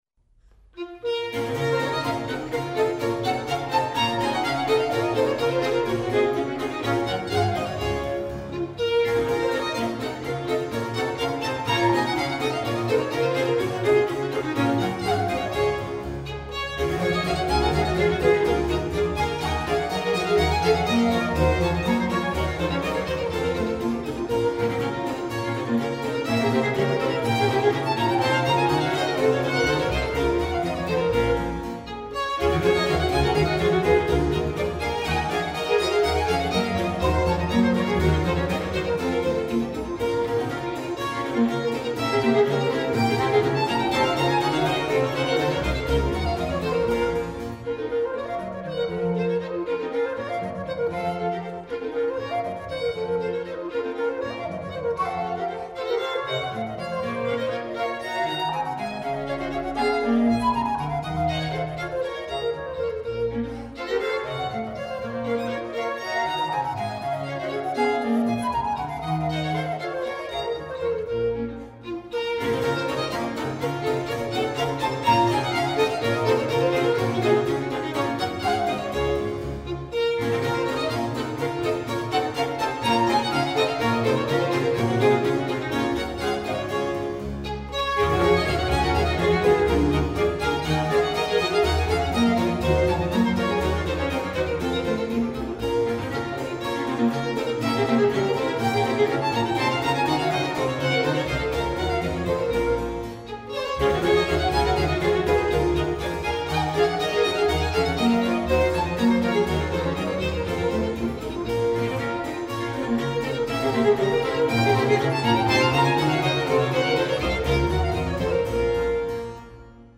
für Traversflöte, Streicher und Basso Continuo